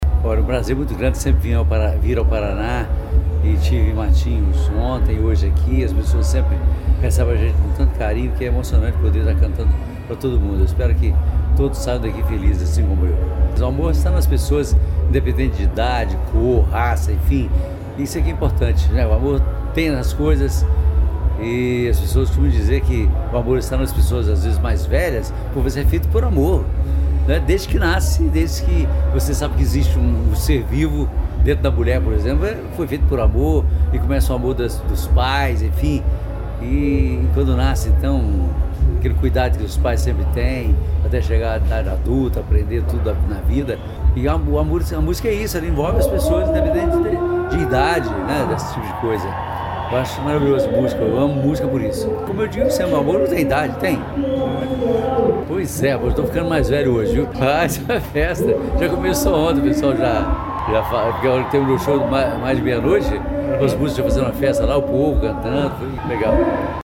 Sonora do cantor Amado Batista sobre o show em Pontal do Paraná no Verão Maior Paraná